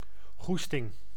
Ääntäminen
IPA: /ʒwa/